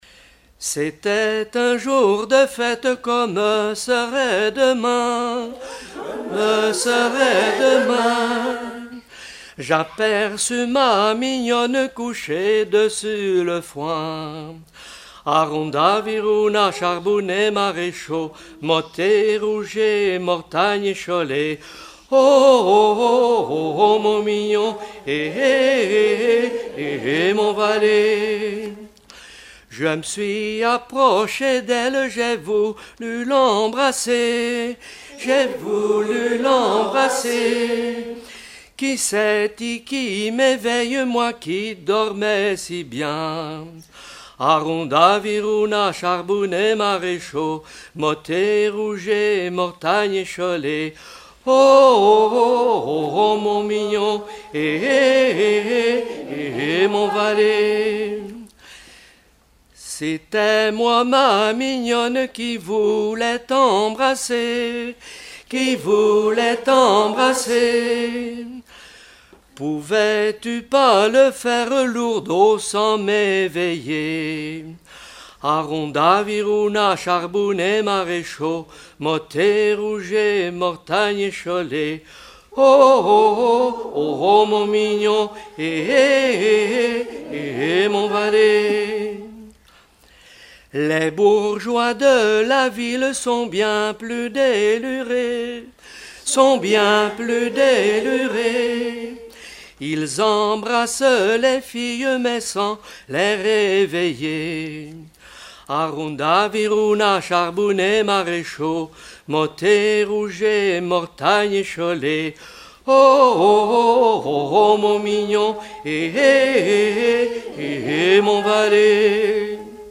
Localisation Tallud-Sainte-Gemme
Genre laisse
Chansons traditionnelles et populaires
Pièce musicale inédite